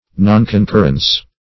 Nonconcurrence \Non`con*cur"rence\, n. Refusal to concur.
nonconcurrence.mp3